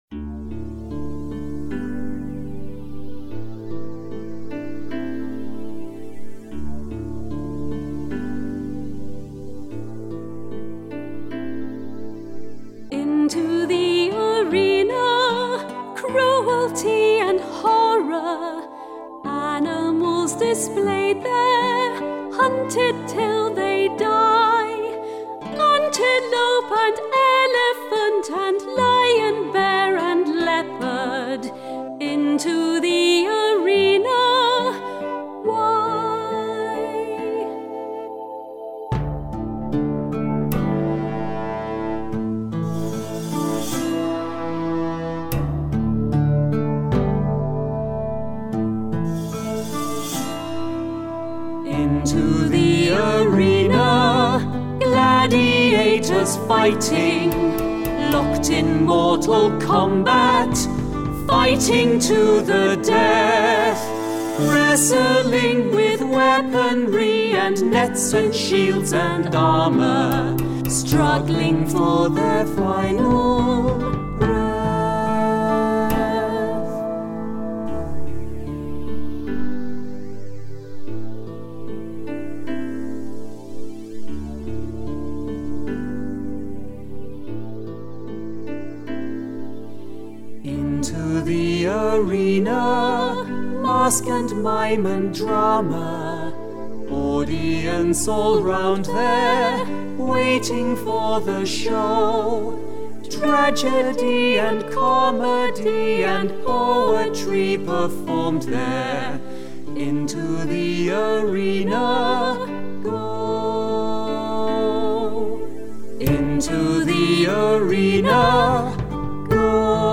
Vocal track
Practise singing the song with the full-vocal track.